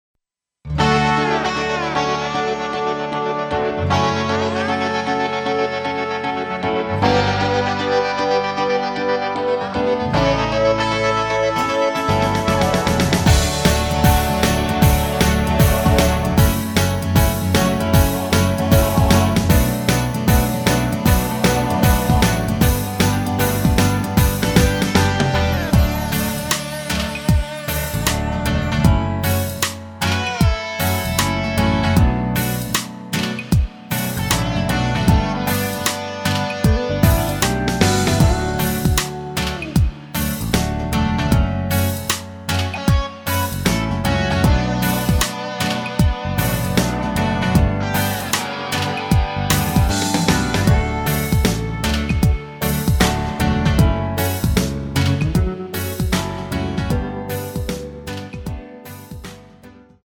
대부분의 남성분이 부르실수 있도록 제작 하였습니다.
앨범 | O.S.T
◈ 곡명 옆 (-1)은 반음 내림, (+1)은 반음 올림 입니다.
앞부분30초, 뒷부분30초씩 편집해서 올려 드리고 있습니다.
중간에 음이 끈어지고 다시 나오는 이유는
위처럼 미리듣기를 만들어서 그렇습니다.